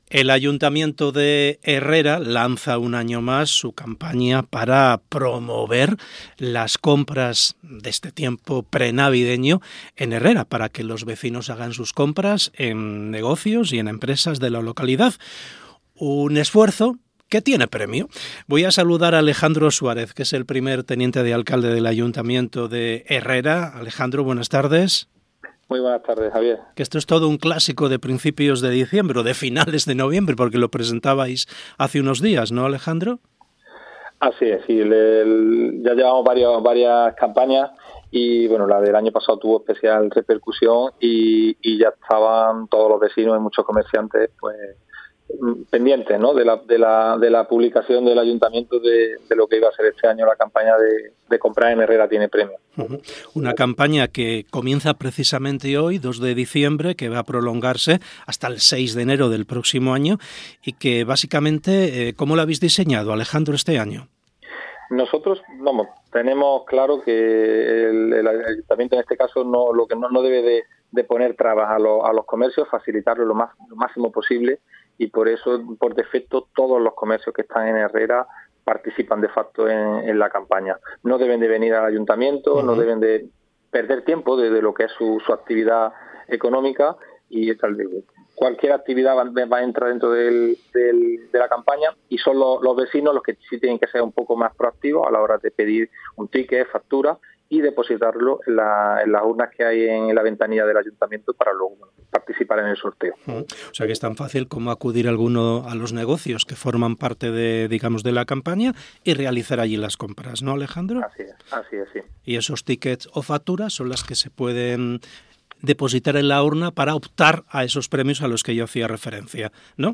Entrevista Alejandro Suarez. Comprar en Navidad tiene premio
Alejandro Suárez, primer teniente de alcalde del Ayuntamiento de Herrera, ha pasado por el programa Hoy por Hoy SER Andalucía Centro y ha puesto en valor la necesidad de fomentar las compras navideñas entre el tejido comercial local.